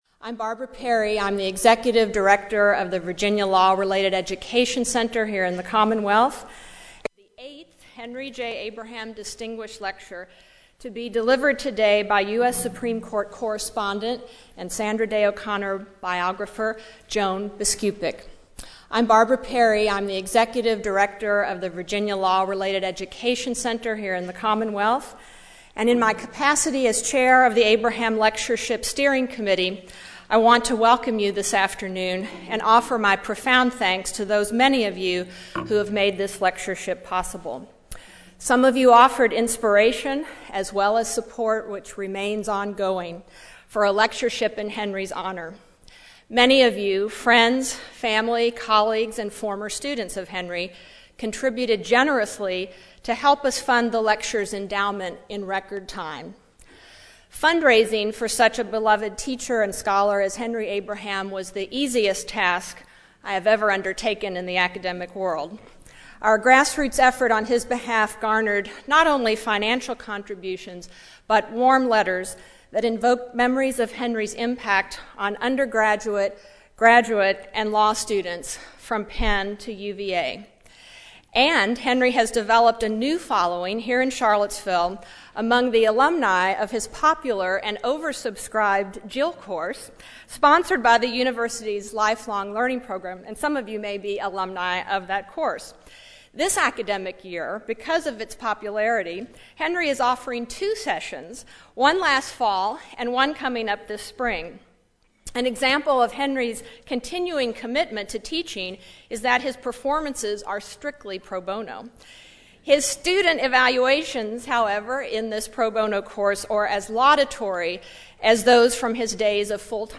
Legal news commentator and reporter Joan Biskupic, discusses her new biography, Sandra Day O’Connor: How the First Woman on the Supreme Court Became Its Most Influential Justice . This event is part of the 2006 Virginia Festival of the Book, and is sponsored by the Thomas Jefferson Center for the Protection of Free Expression.